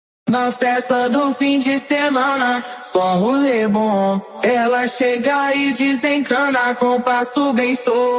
Funk Carioca